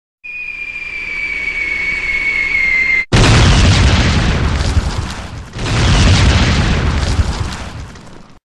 Play Patlama Sesi - SoundBoardGuy
Play, download and share Patlama Sesi original sound button!!!!
patlama-sesi.mp3